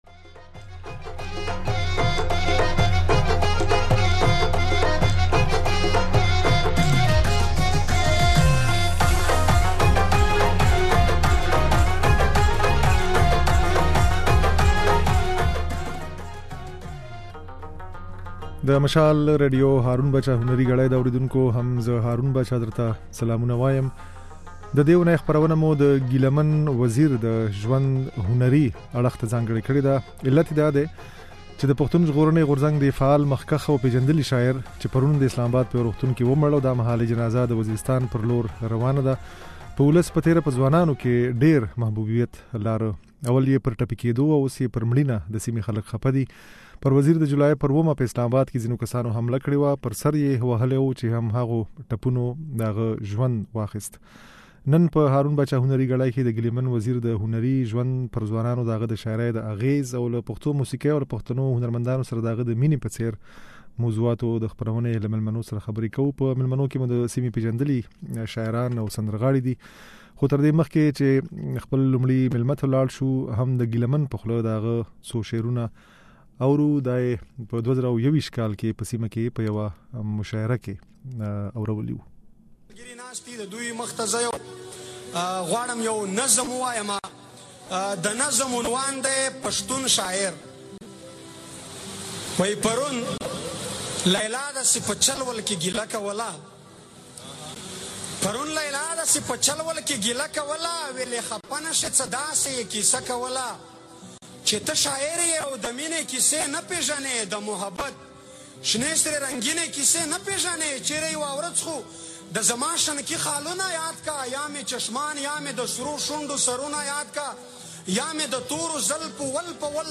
په خپرونه کې د ګيله من وزير د شعر، له موسيقۍ سره يې د مينې او د ولسي محبوبيت د لاملونو په اړه د سيمې د پېژندليو سندرغاړو او شاعرانو خبرې اورېدای شئ.